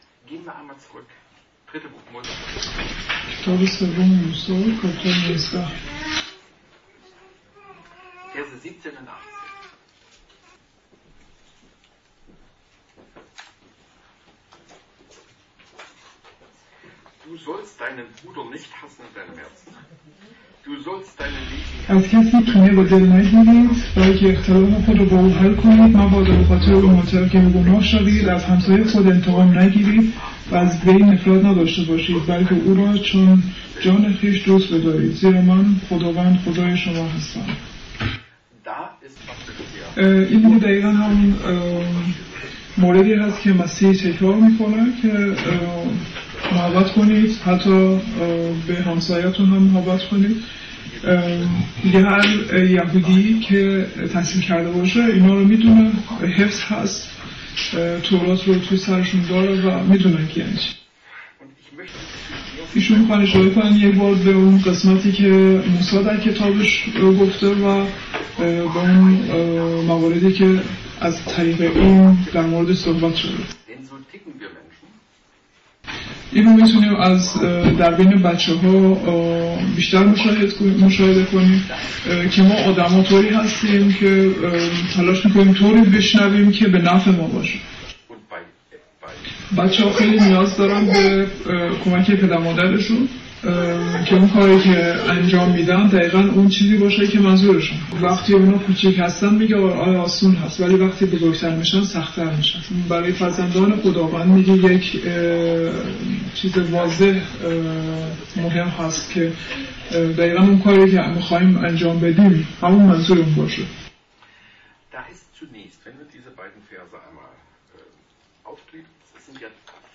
Mose 19,17-18 zum Thema Nächstenliebe | Übersetzung in Farsi